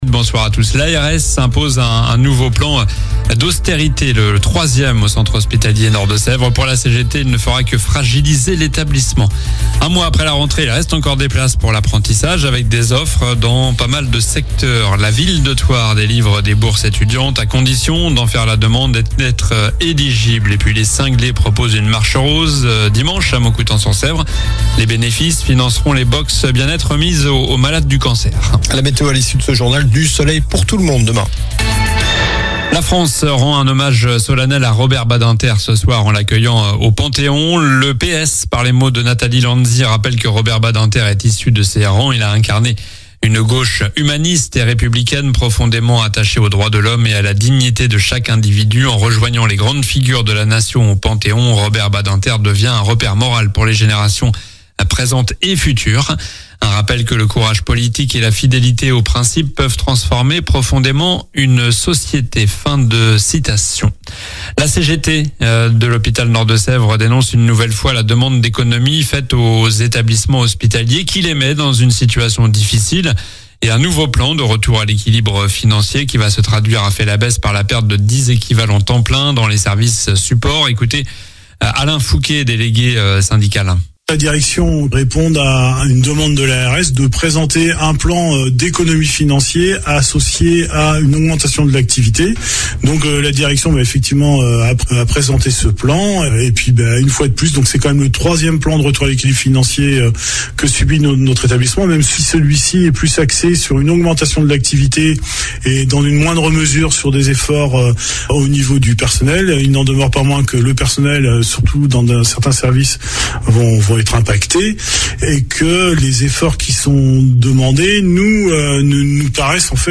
Journal du jeudi 9 octobre (soir)